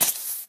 sounds / mob / creeper